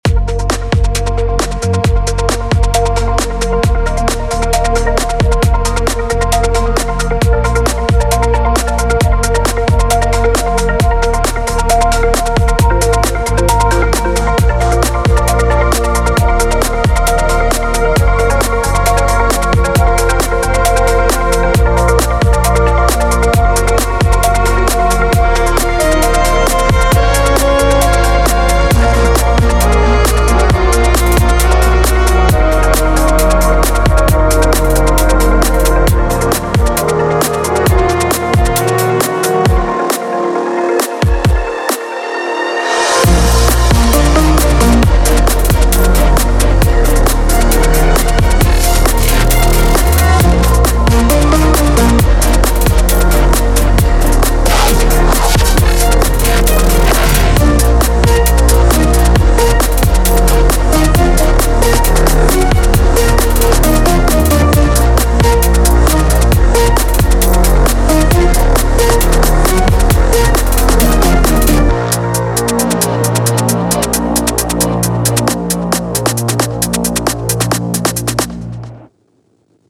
love the atmosphere. The mix is also very clean.